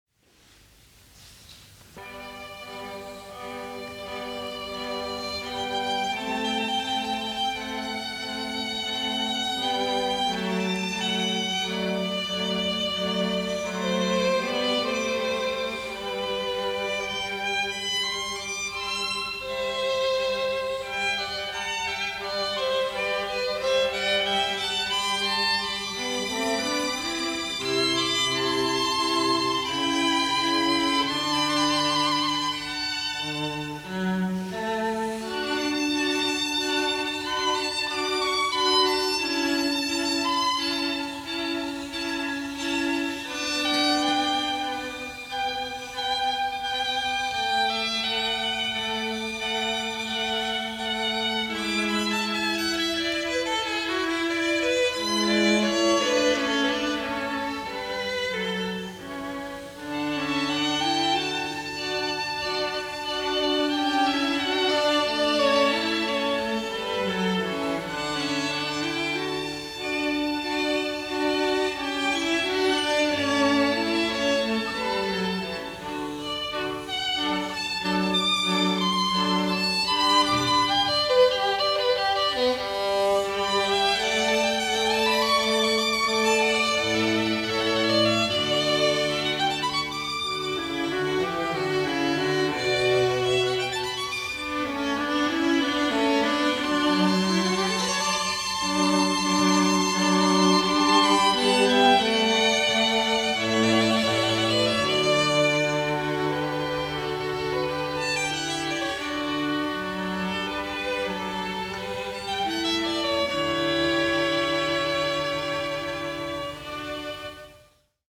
KlarEnKvartettKlassiker — Oslo string quartet
KlarEnKvartettKlassiker av Oslo Strykekvartett Oslo Strykekvartett har en stor samling av opptak fra konserter helt tilbake til den aller første, den 6. april 1991.
Gamle Logen